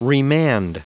Prononciation du mot remand en anglais (fichier audio)
Prononciation du mot : remand